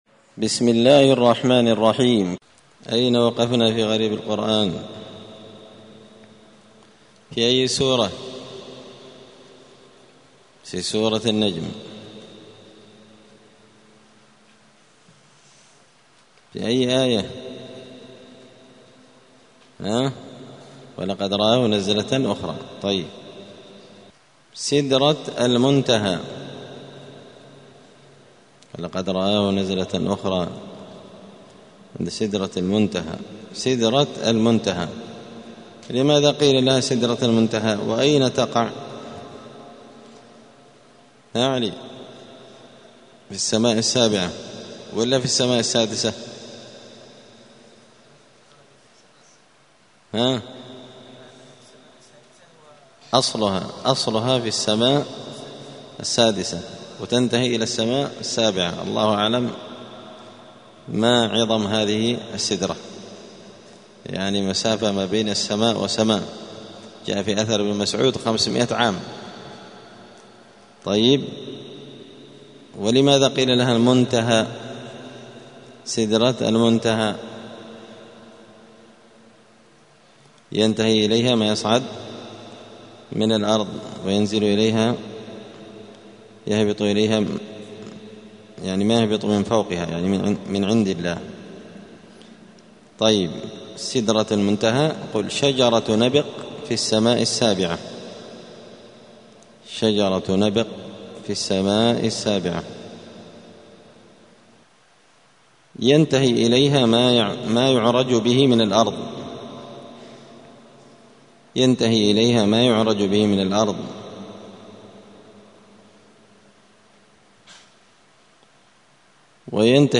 دار الحديث السلفية بمسجد الفرقان بقشن المهرة اليمن 📌الدروس اليومية